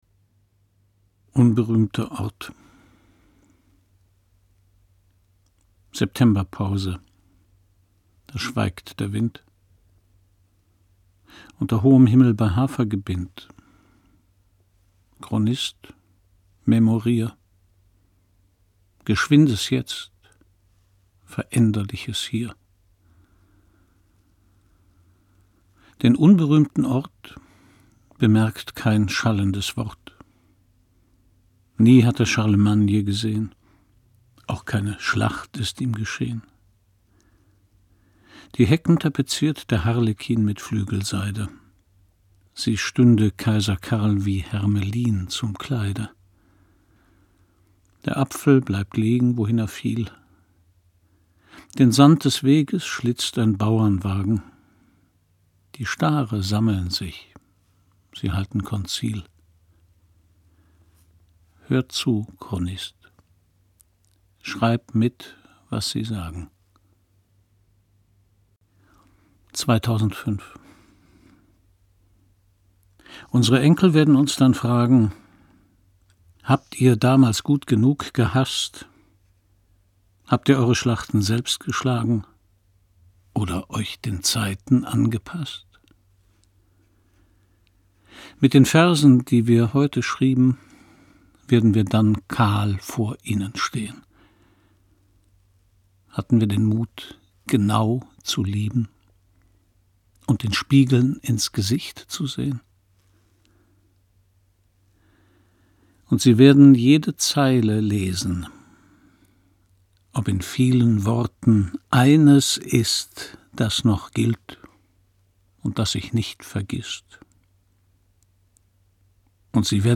Christian Brückner (Sprecher)